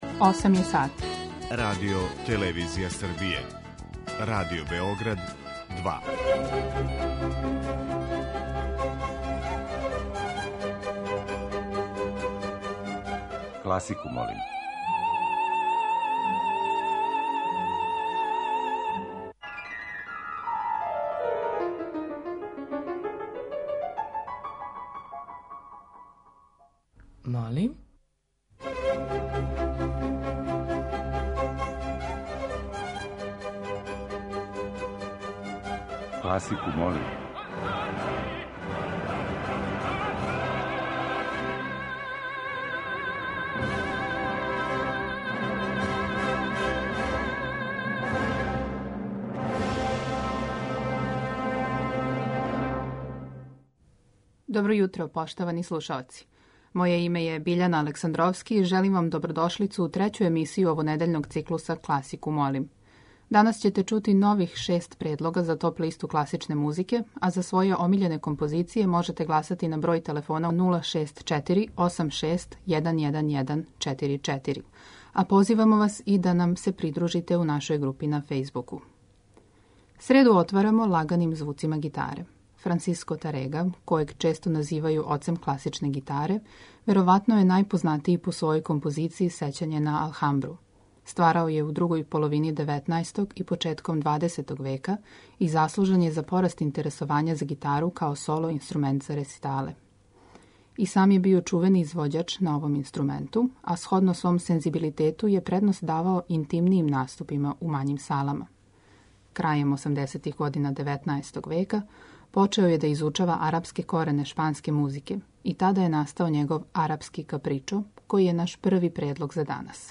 Избор за недељну топ-листу класичне музике Радио Београда 2